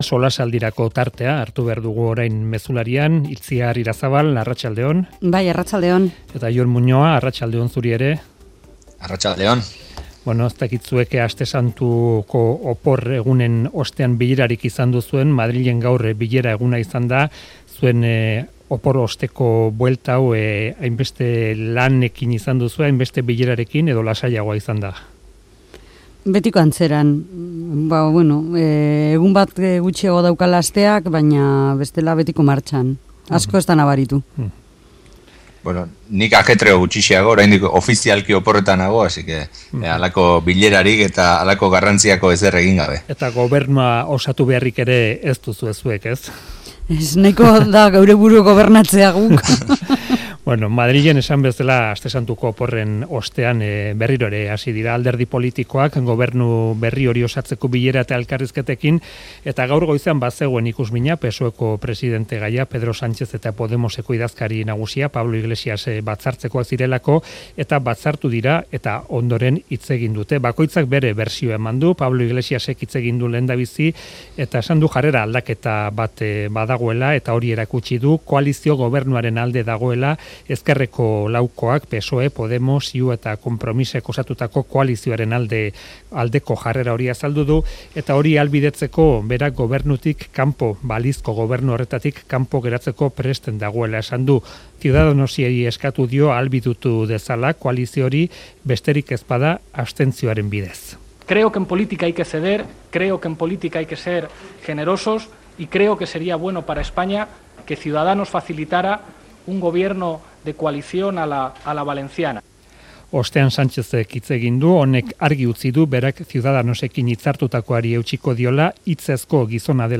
tertulian